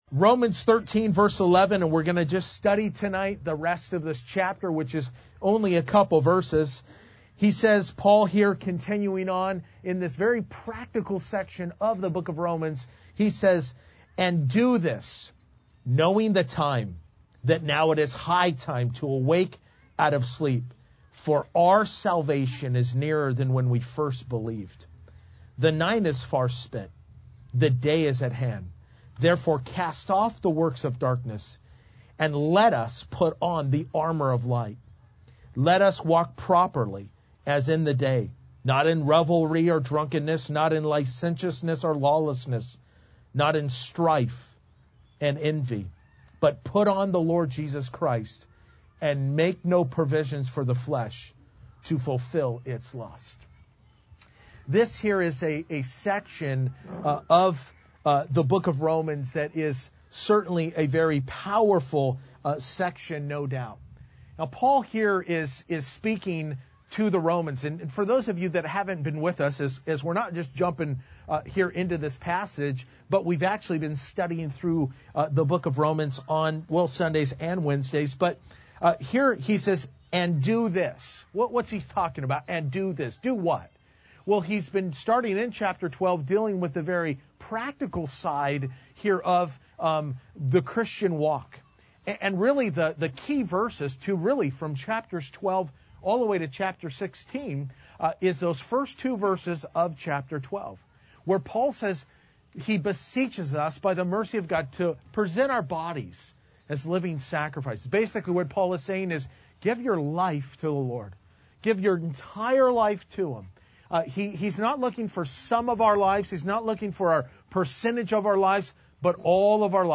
Verse by Verse-In Depth